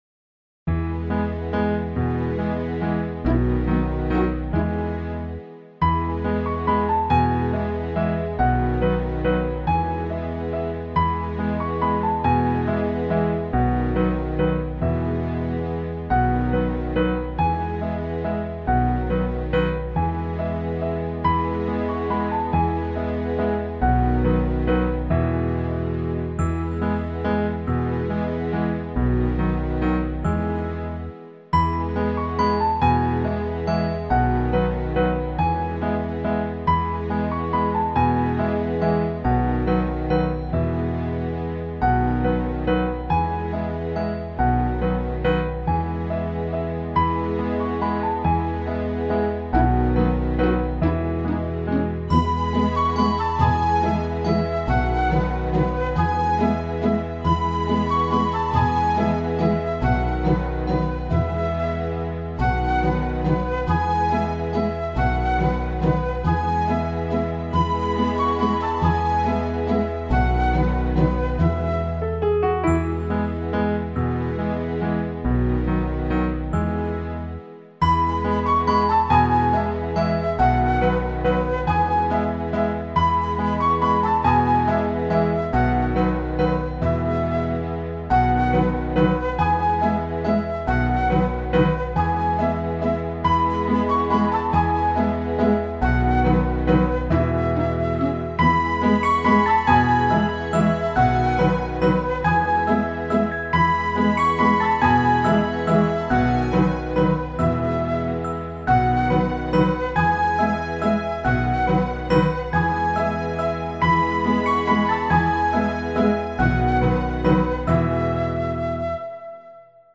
Children’s song, Germany
instrumental, 2:02 – 3/4
Kids Karaoke Song (Instrumental) YouTube License